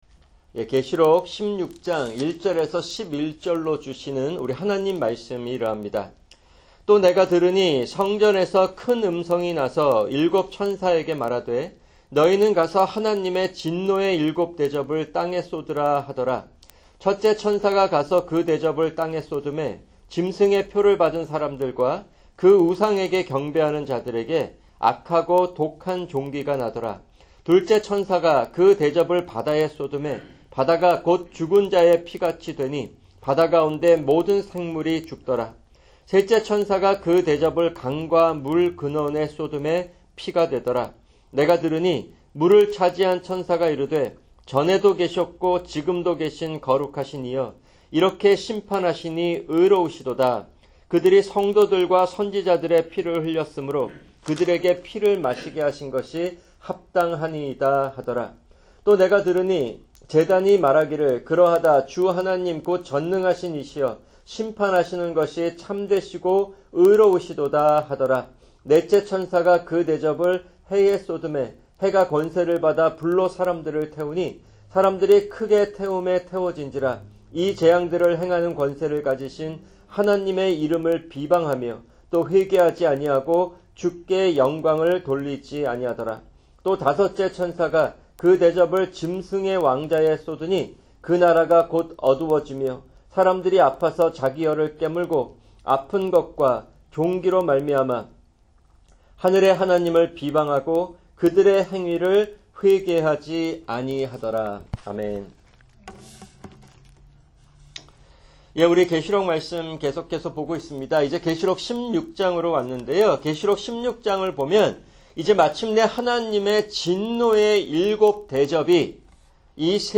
[주일 설교] 학개(7) 2:10-19(1)